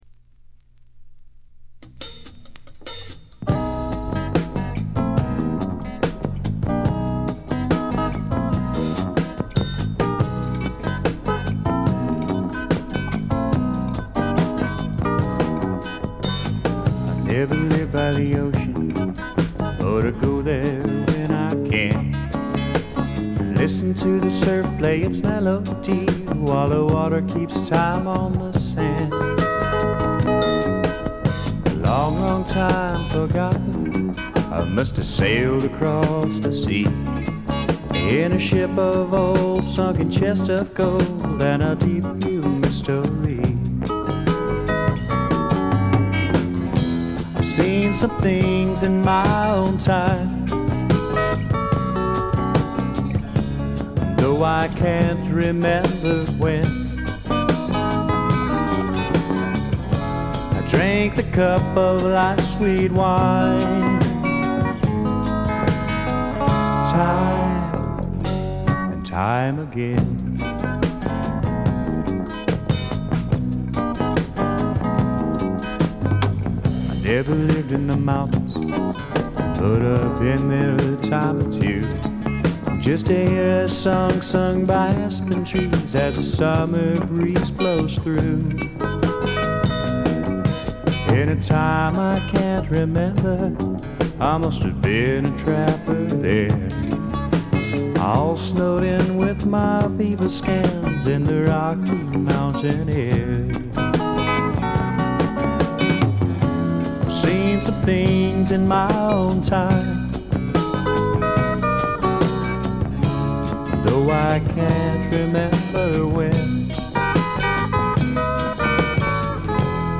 ほとんどノイズ感無し。
カントリー風味の曲とかファンキーな曲とかもありますが、クールでちょいメロウな曲調が特に素晴らしいと思います。
試聴曲は現品からの取り込み音源です。
Recorded at - Real To Reel , Garland, Texas